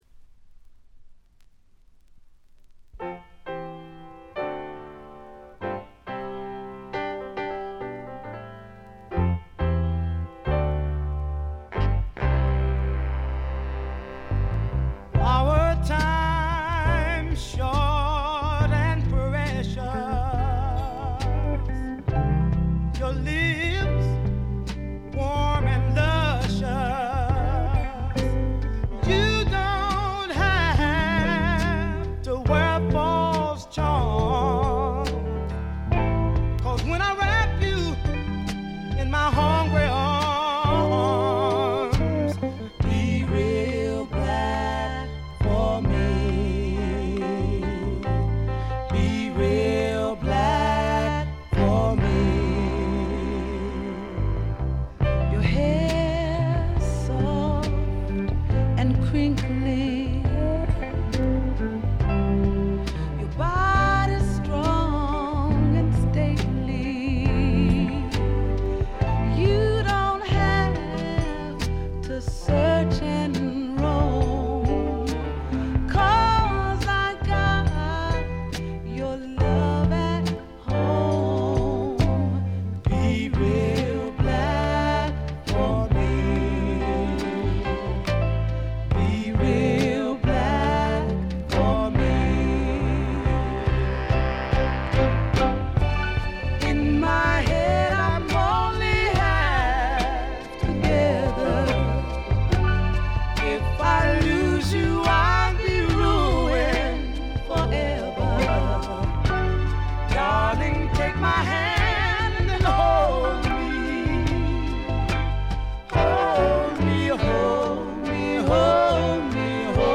ごくわずかなノイズ感のみ。
頂点を極めた二人の沁みる名唱の連続でからだが持ちませんね。
試聴曲は現品からの取り込み音源です。